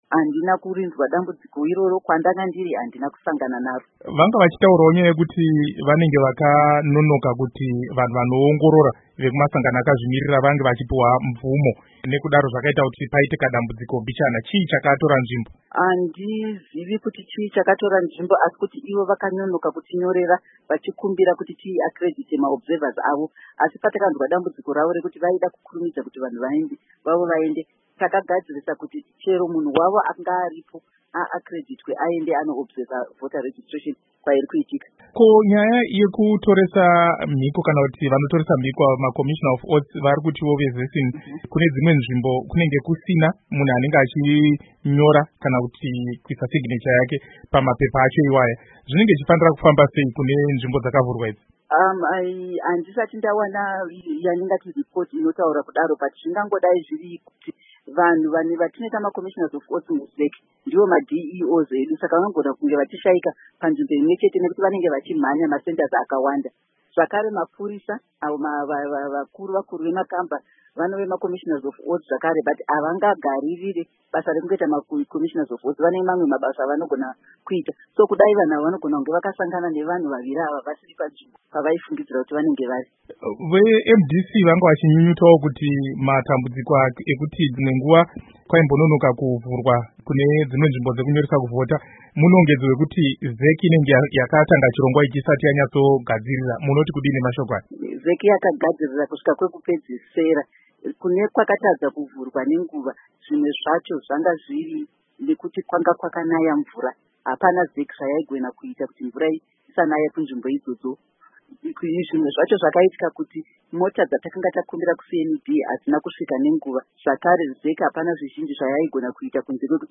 Hurukuro naAmai Rita Makarau